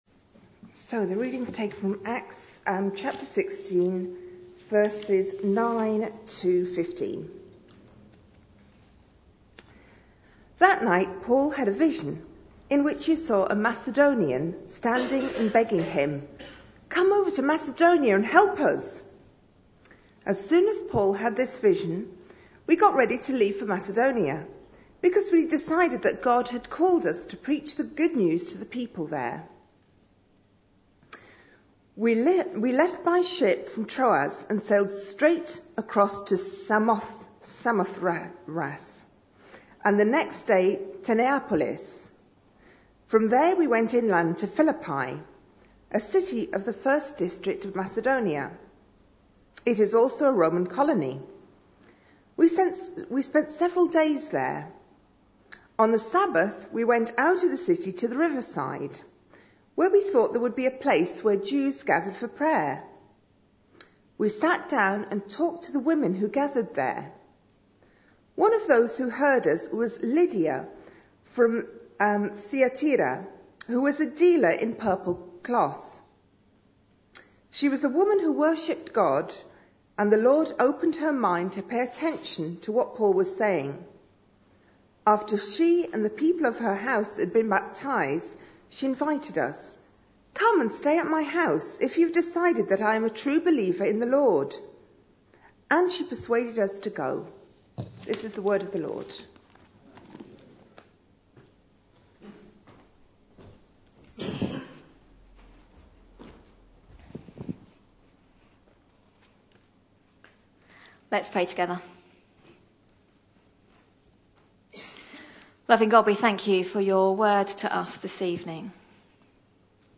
A sermon preached on 15th June, 2014, as part of our Connecting With Culture series.